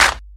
Clap 15.wav